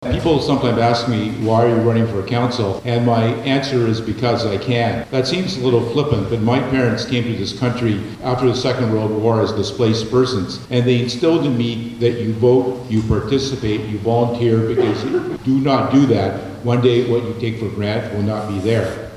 The nominees gathered at McNab School September 22nd for an All-Candidate Forum, hosted by the Greater Arnprior Chamber of Commerce.